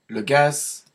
• ÄäntäminenFrance (Paris):
• IPA: [dy ɡaz]